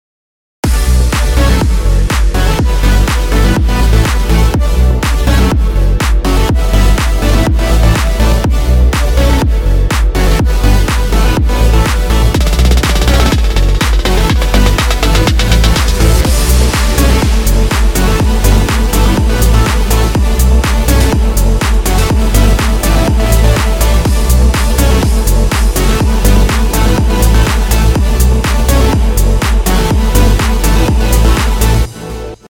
ואני יודע שצריך להחליש לא הרבה אבל בקטנה את הבס המפלצתי הזה…